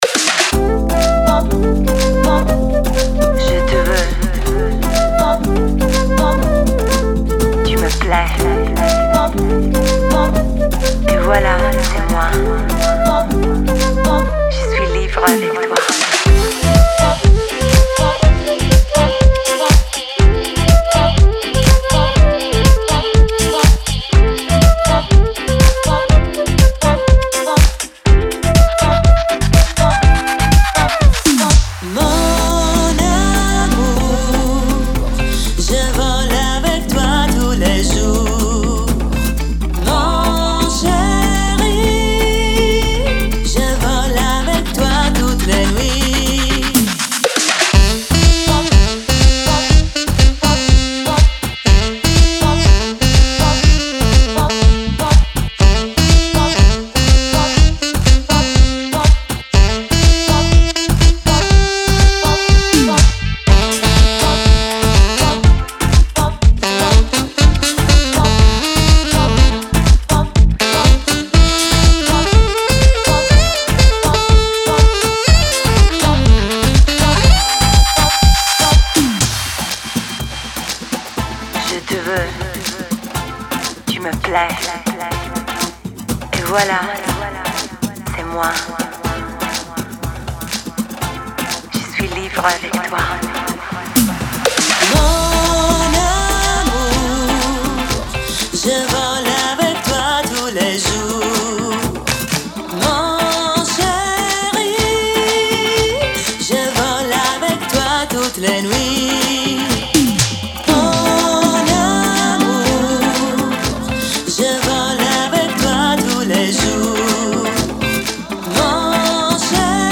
Žanr DJ Pop